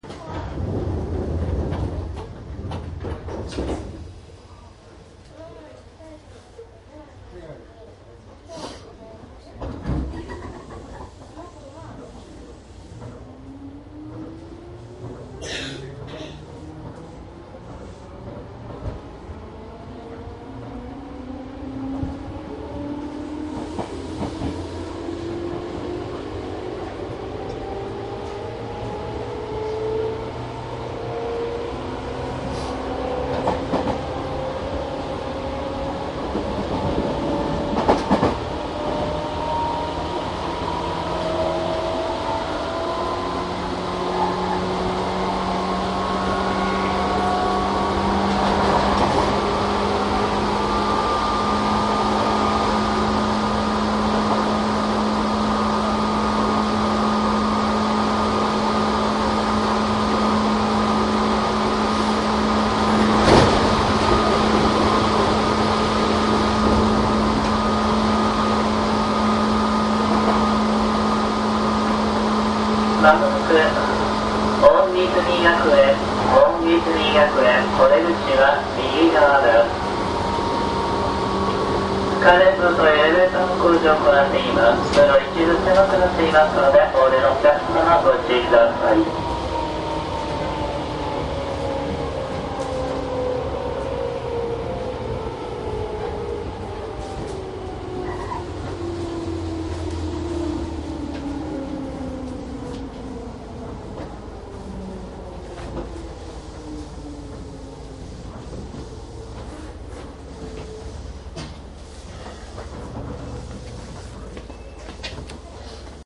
西武101系・非ワンマン  走行音♪
１０１系は池袋線以外はすべて低音車の車両にて収録。
低音車と高音車で走行音はかなり違います。
■【普通】保谷→池袋 モハ243
マスター音源はデジタル44.1kHz16ビット（マイクＥＣＭ959）で、これを編集ソフトでＣＤに焼いたものです。